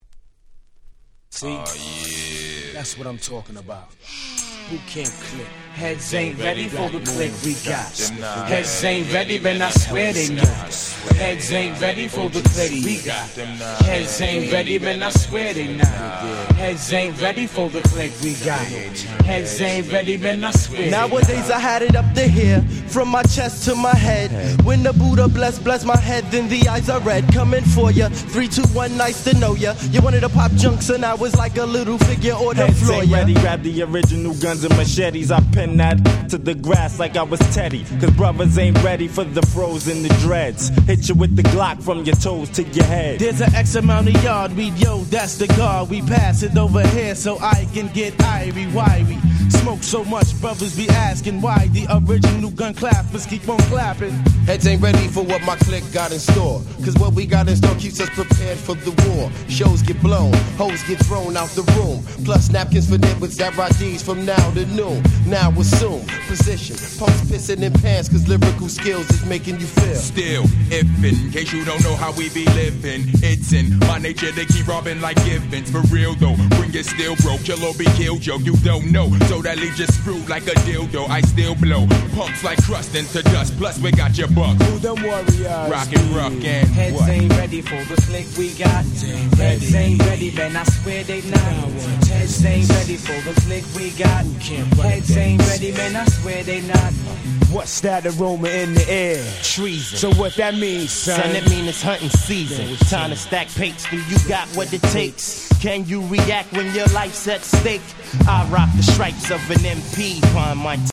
95' Smash Hit Hip Hop / Underground Hip Hop !!